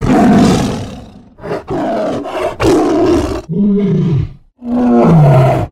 tiger-sound